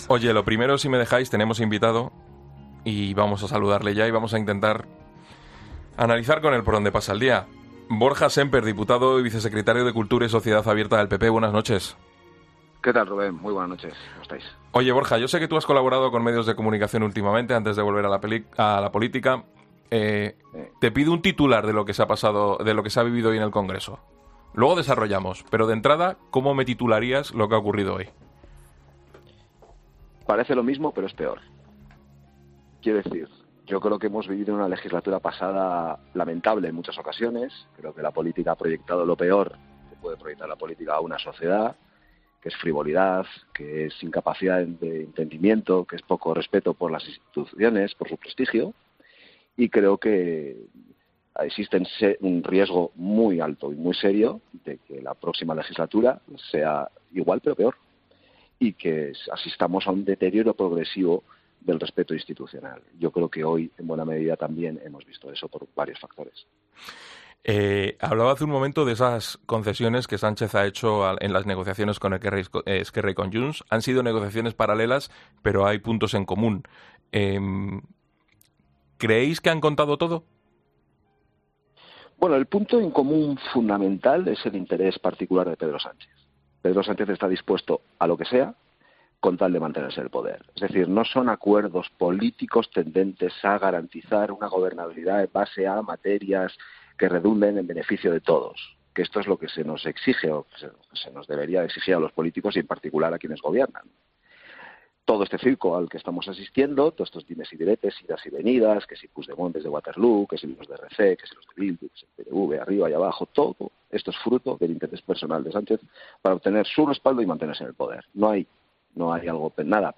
Vuelve a escuchar la entrevista al diputado del PP, Borja Sémper, en 'La Linterna' de COPE